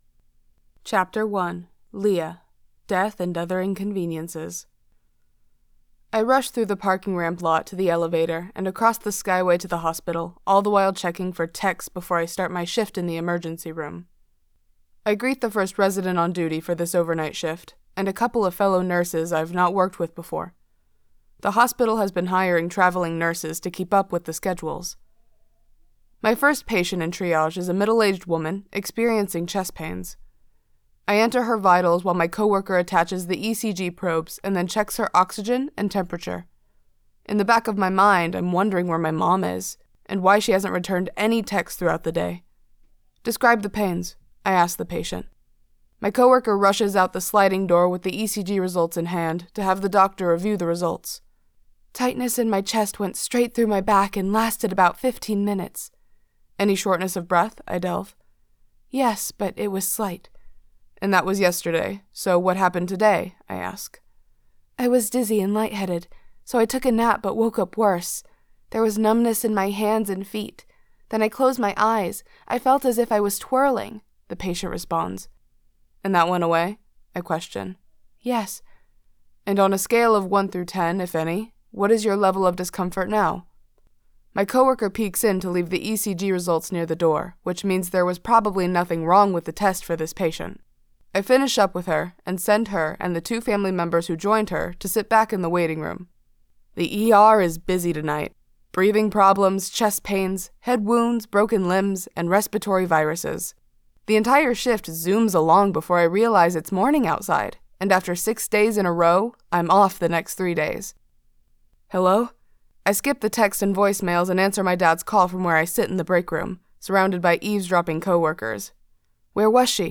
Echoes Of The Past (Audio Book)
American, Southern American